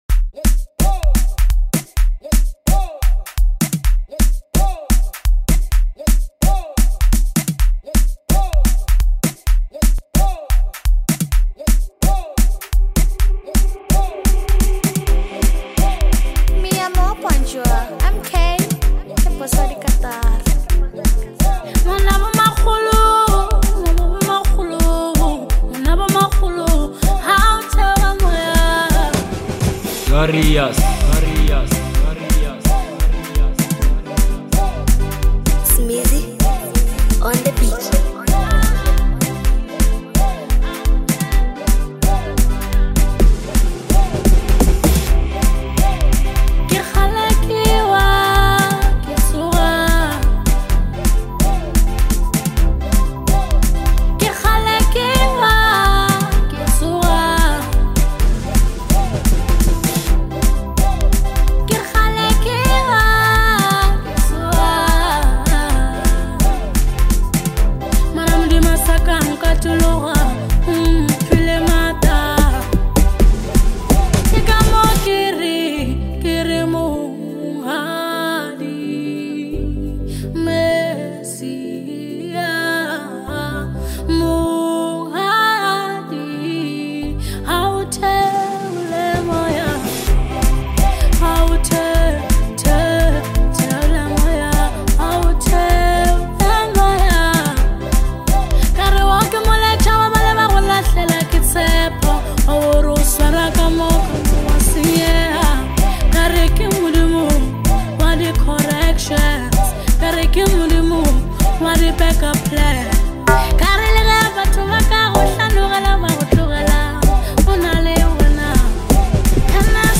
a lively track
vibrant rhythm